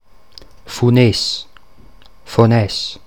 Fonès_Mundart.mp3